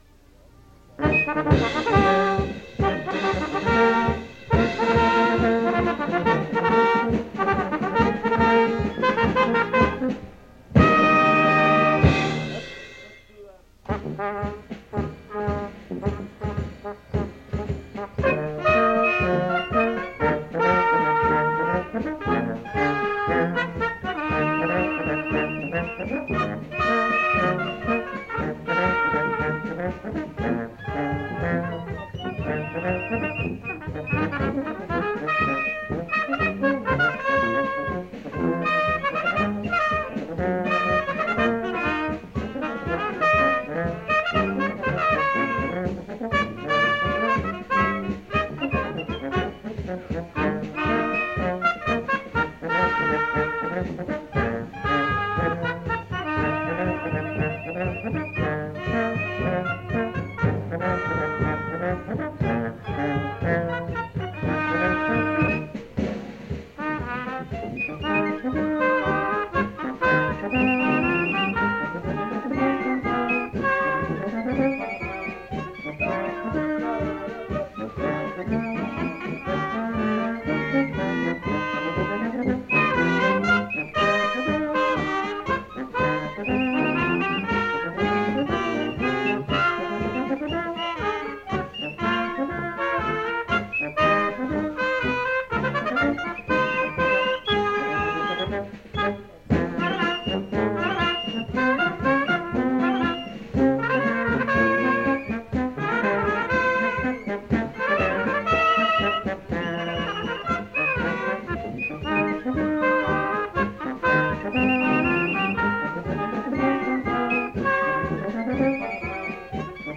Now you can listen to any or all of the playlist from that first WJU Convention in Key Biscayne, Florida in January,1973.